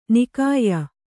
♪ nikāyya